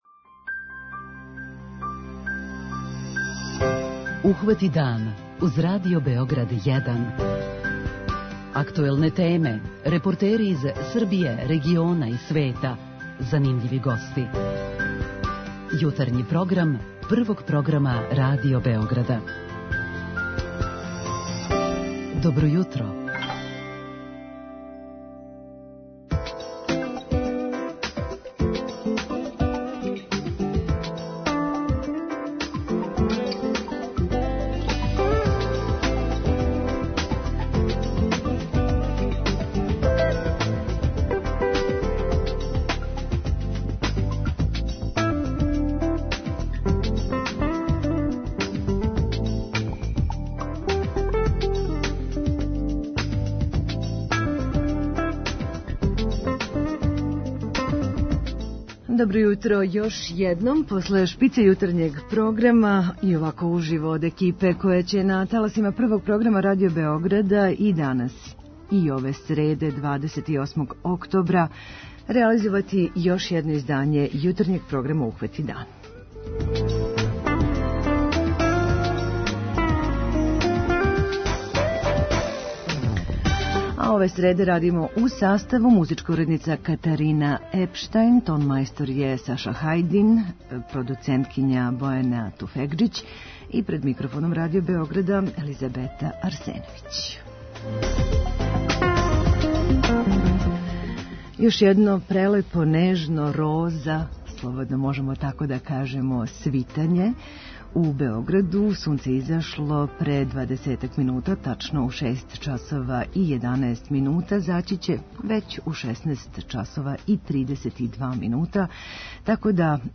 Reporter Jutarnjeg programa biće pred početak probe u jednoj beogradskoj gimnaziji, a vama ćemo, u "Pitanju jutra" ponuditi da kažete svoje mišljenje o tome šta bi suštinski novo mogao da donese povratak velike mature, nekada davno nestao sa uvođenjem tzv. usmerenog srednjoškolskog obrazovanja i može li to da u potpunosti zameni prijemne ispite.